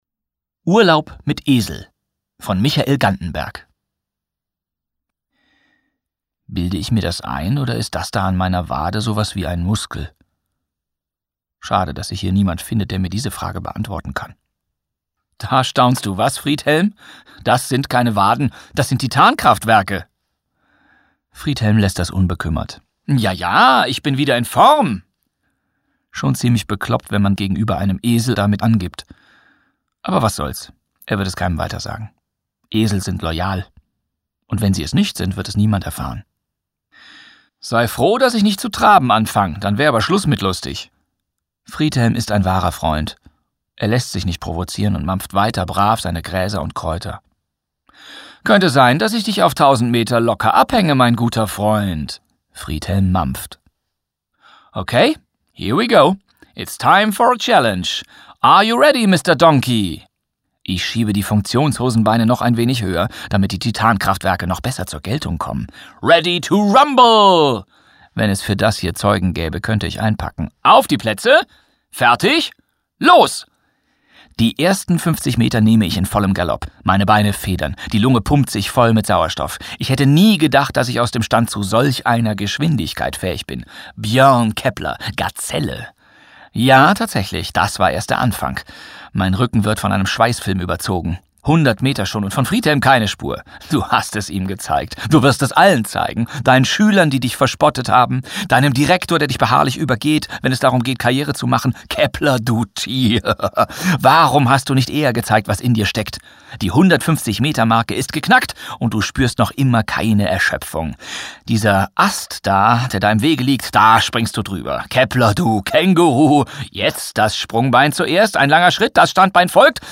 Bastian Pastewka (Sprecher)
2020 | 1. Auflage, Gekürzte Ausgabe